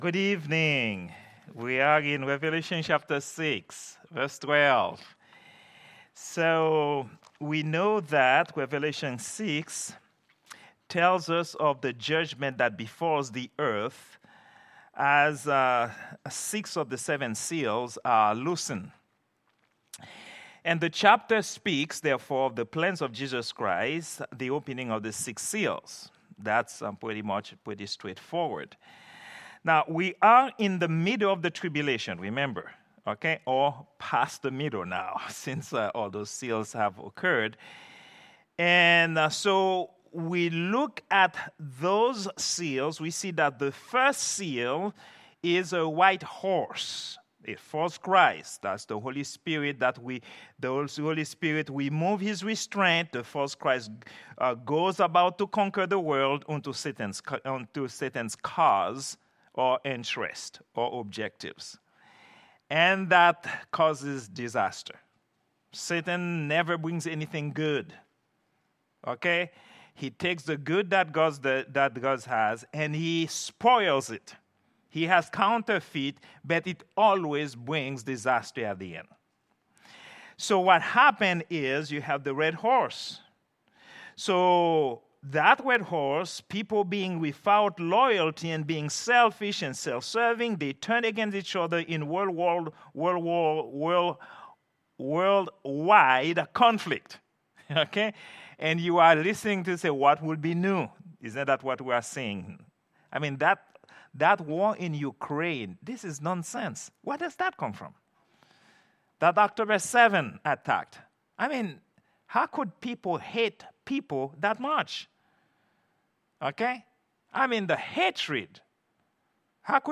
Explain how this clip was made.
Prayer_Meeting_06_19_2024.mp3